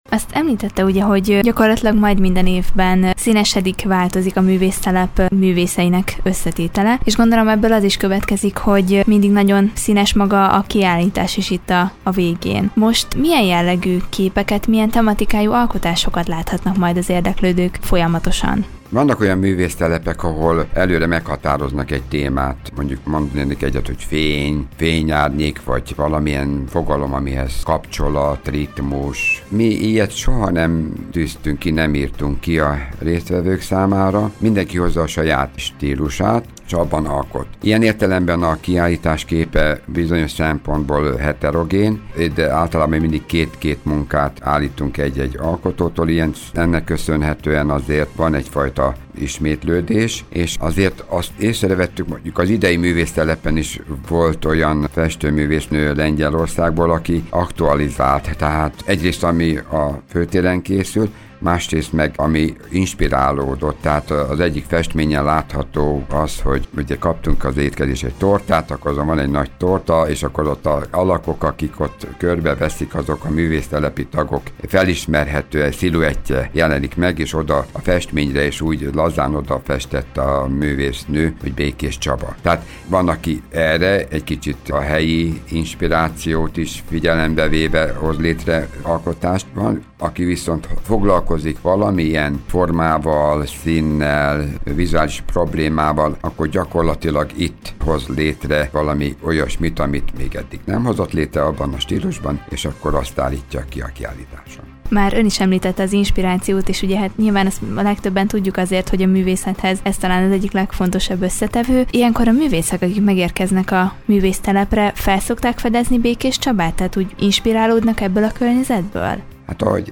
Vele beszélgetett tudósítónk a Művésztelep indulásáról, az idei munkáról valamint a Művésztelepet záró kiállításról.